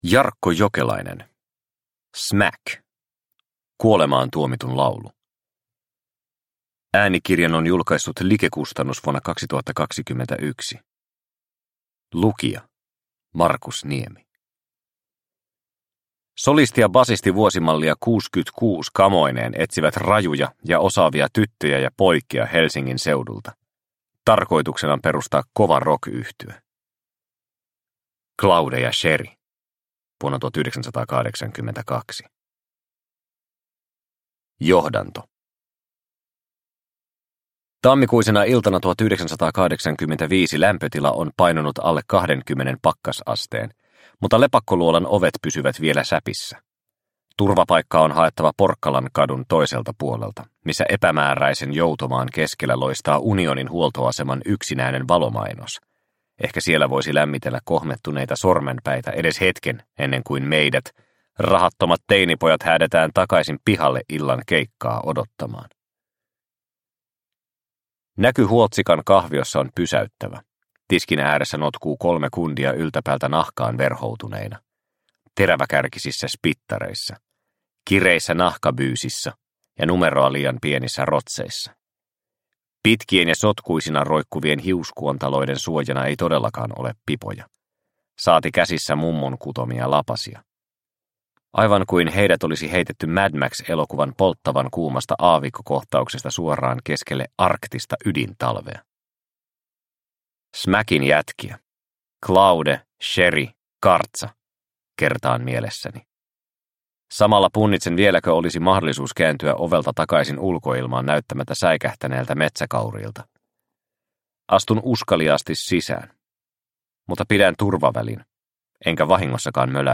Smack – Ljudbok – Laddas ner